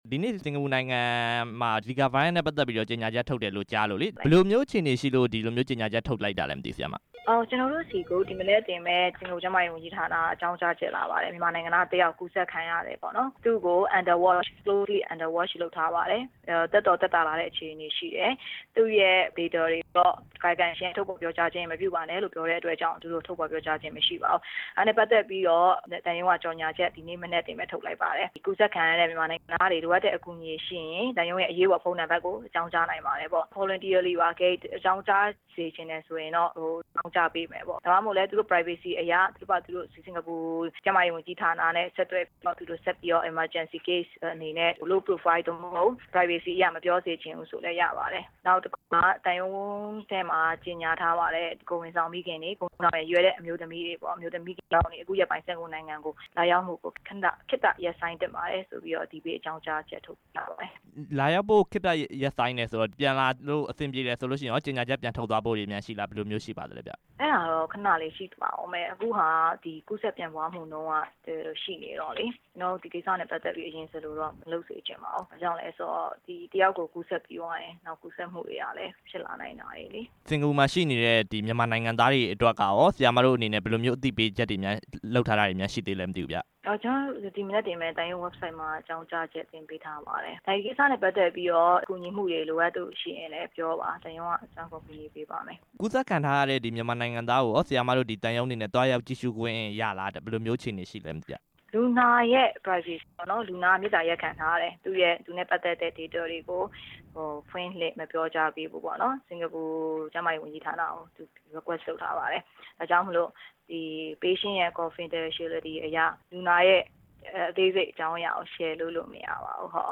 ဆက်သွယ်မေးမြန်းထားပါတယ်။